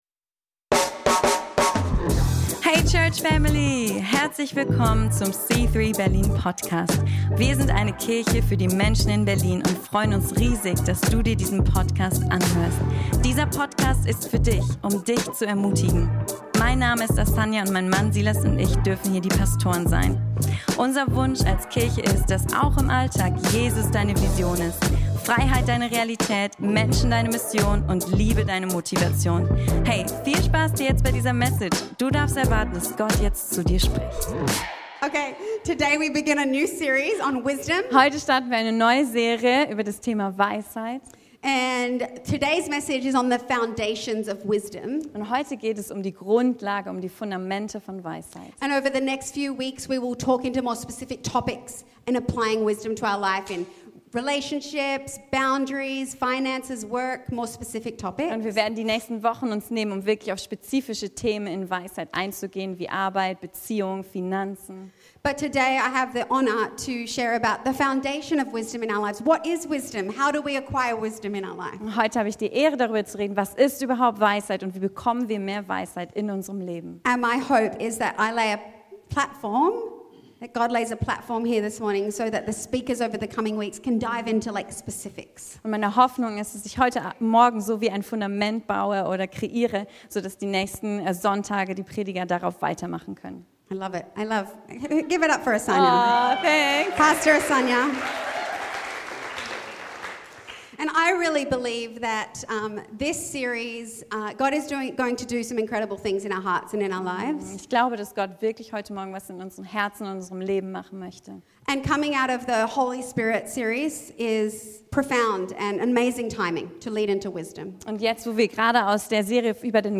Tauche ein in eine inspirierende Predigt über die Grundlagen der Weisheit.